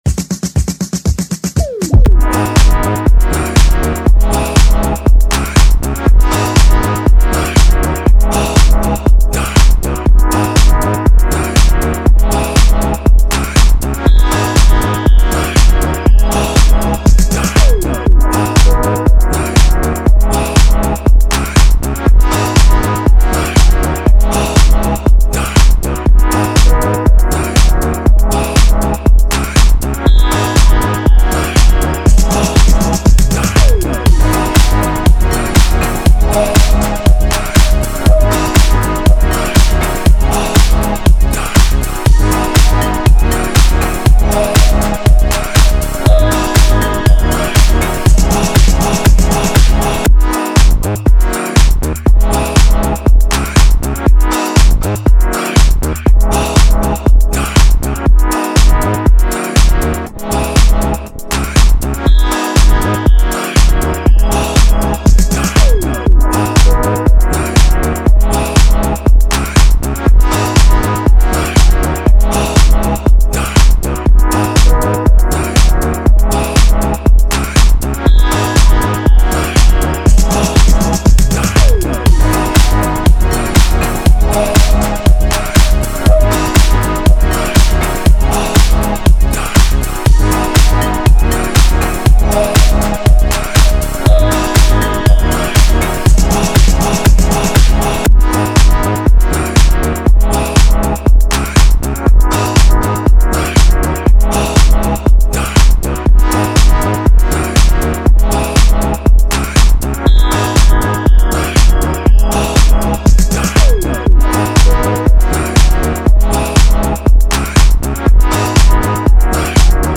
Pop, Dance
G#Minor